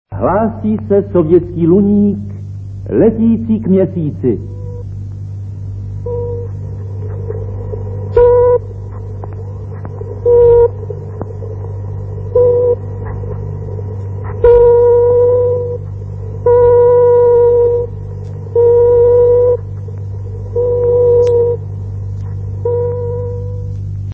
Il faut garderer à l'esprit que la plupart des enregistrements sont anciens.